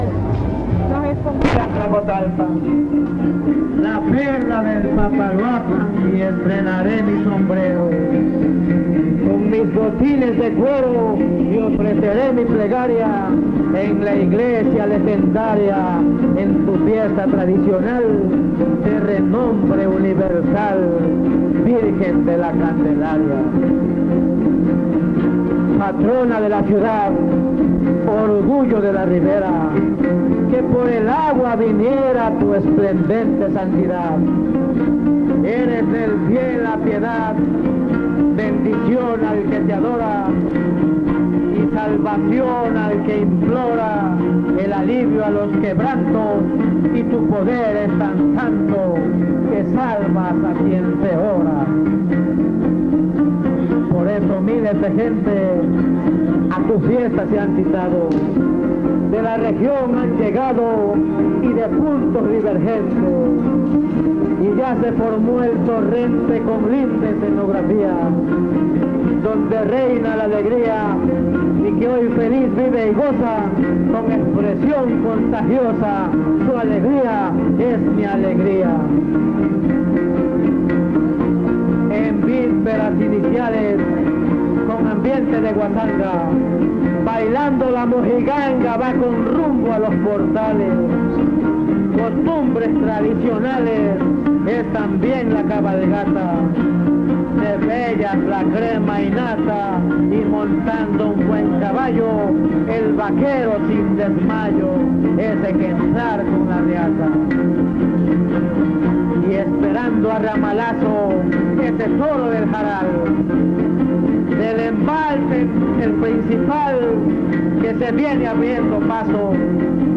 Fiesta de la Candelaria